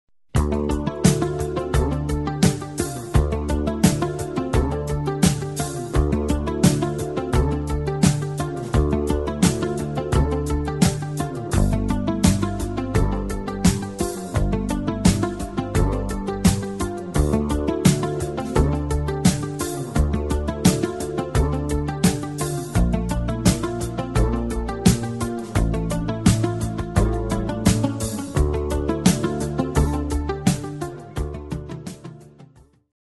мелодии для будильника